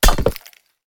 axe-mining-ore-1.ogg